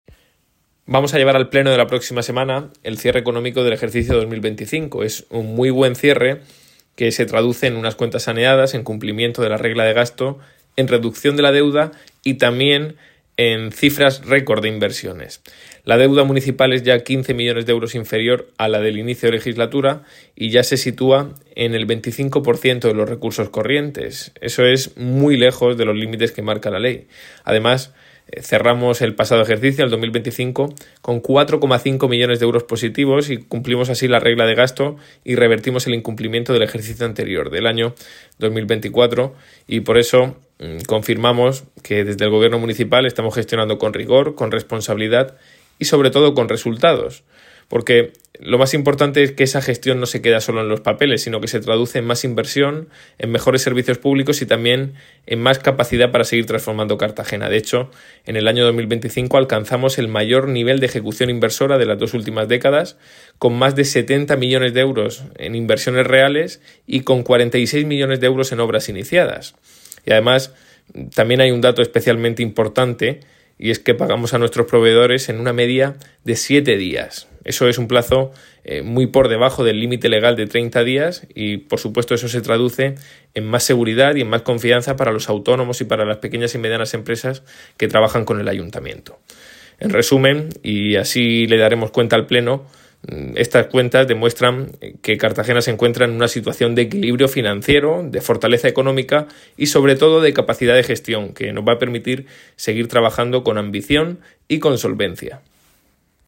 Enlace a Declaraciones del concejal Ignacio Jáudenes sobre evolución de las cuentas al cierre del ejercicio 2025